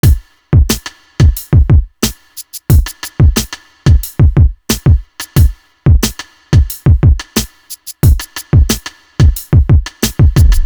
Feel Me Drum.wav